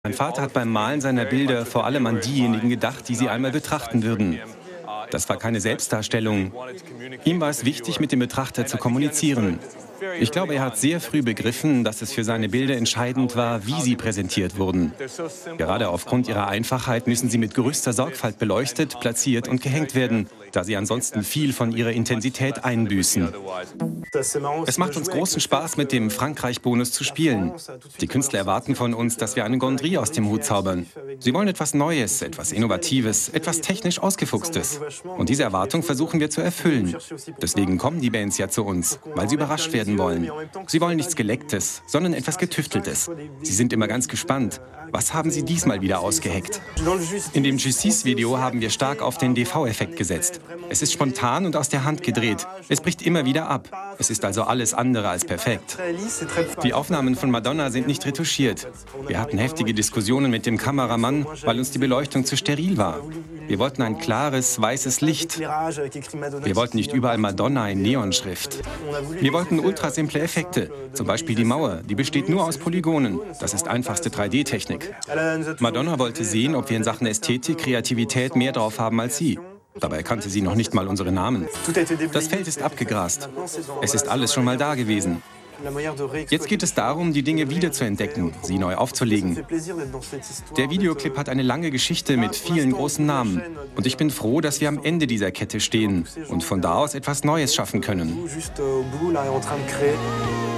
Professioneller deutscher Sprecher für Voice-Over, Reportage Synchron Werbung Doku Lernsoftware Telefon Präsentationen.
Kein Dialekt
Sprechprobe: Sonstiges (Muttersprache):
german voice over artist